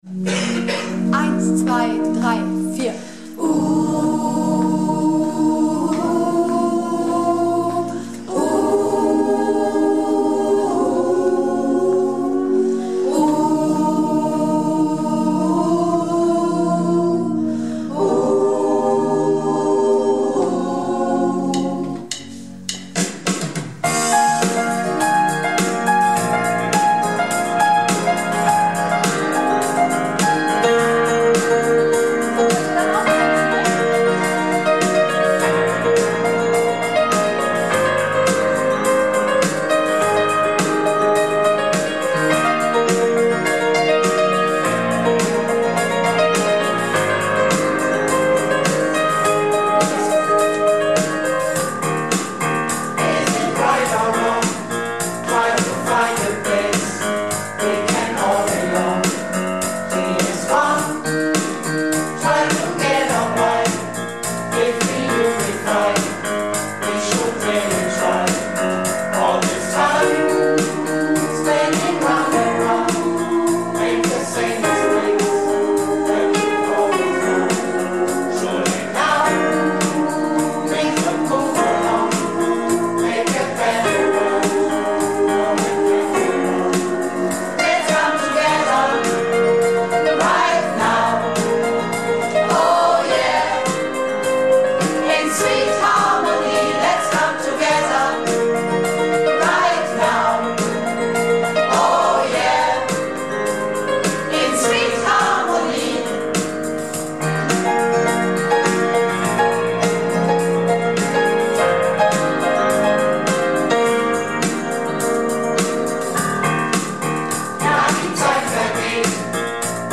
Projektchor "Keine Wahl ist keine Wahl" - Probe am 06.08.19